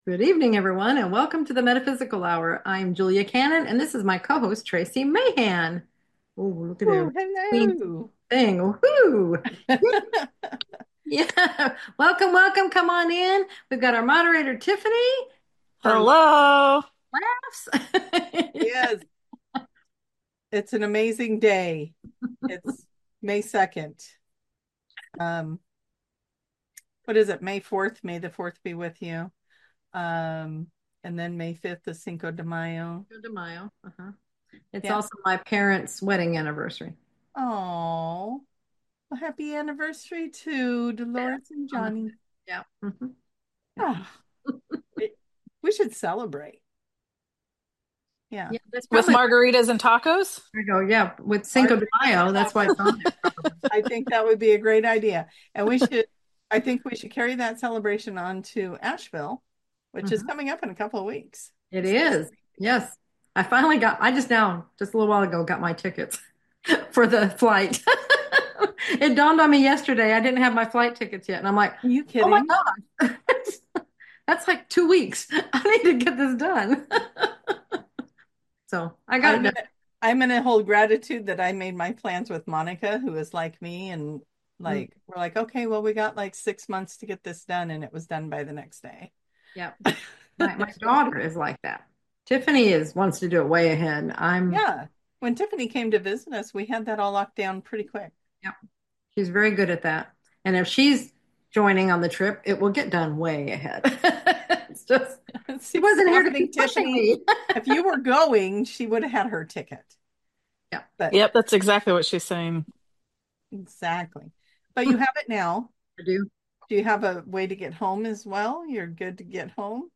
The Metaphysical Hour Talk Show